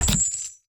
UIMvmt_Menu Medieval Chains 02.wav